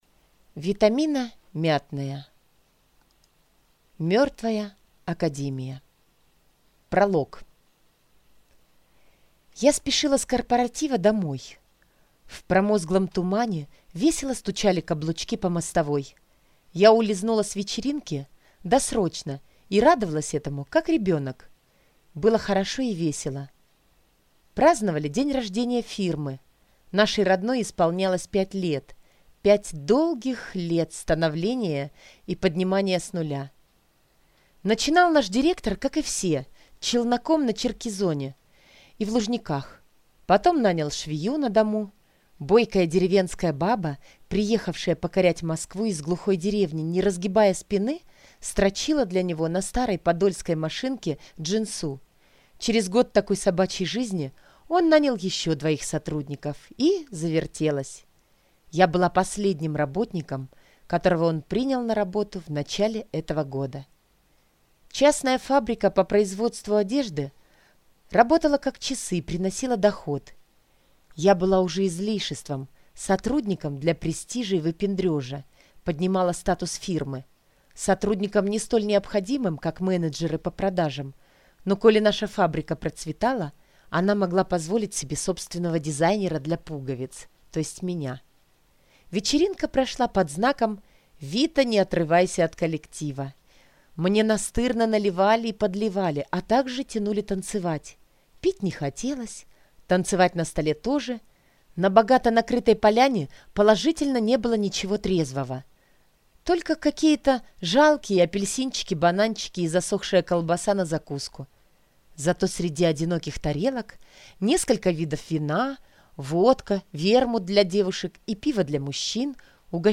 Аудиокнига Мертвая Академия | Библиотека аудиокниг